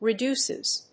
/rɪˈdusɪz(米国英語), rɪˈdu:sɪz(英国英語)/